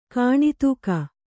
♪ kāṇi tūka